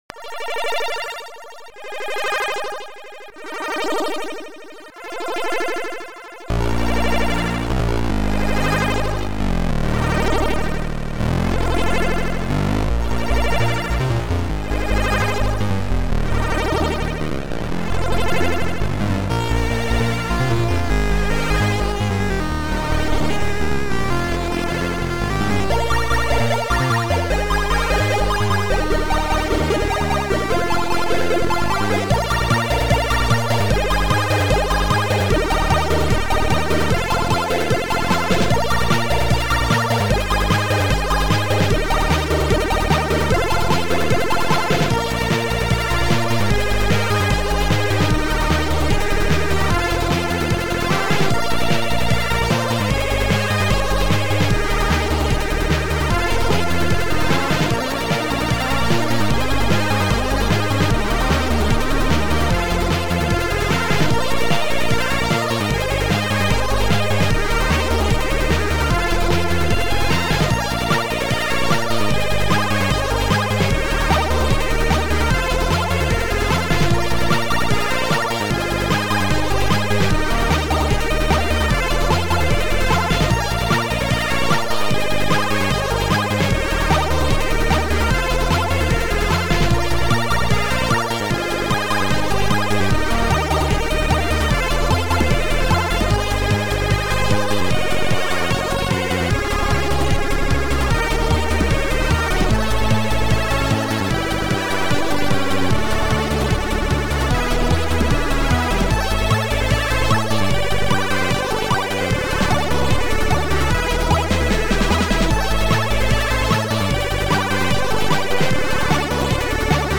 SidMon II Module